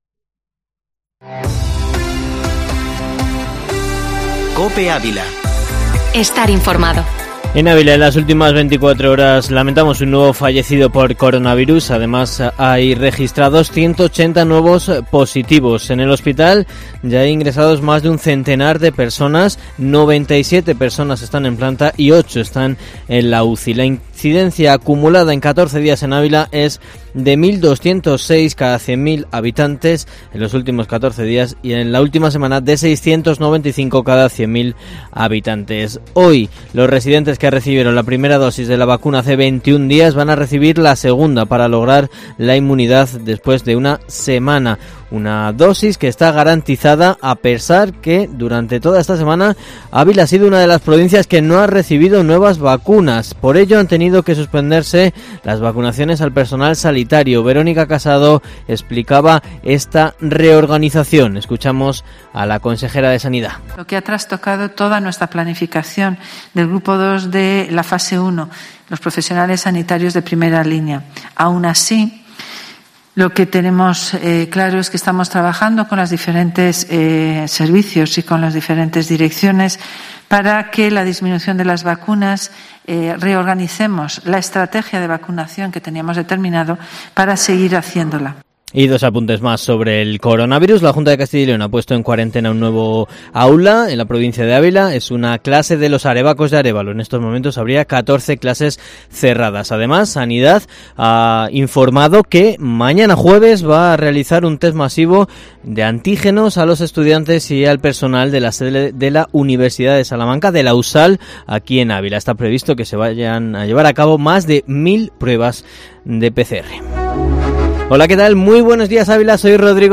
Informativo matinal Herrera en COPE Ávila 20/01/2021